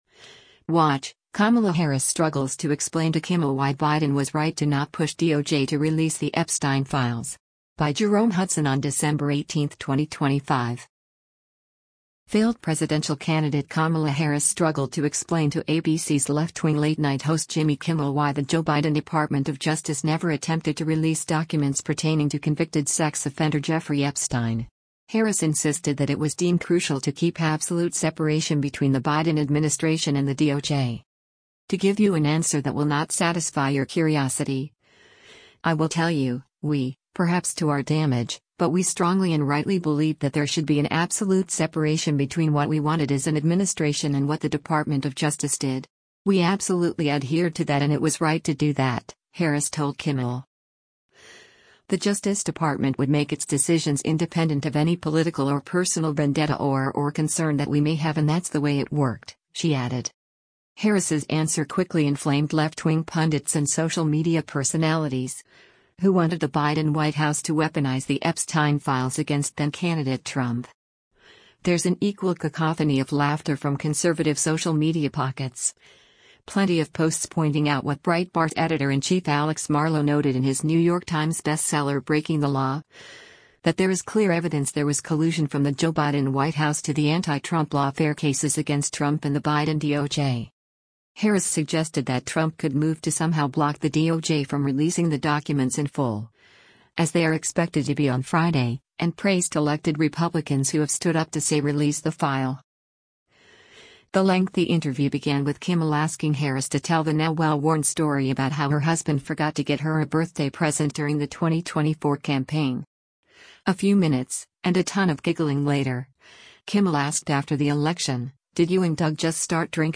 A few minutes, and a ton of giggling later, Kimmel asked, “After the election, did you and Doug just start drinking?”
“You think.” Harris said to raucous laughter from Kimmel and the live studio audience.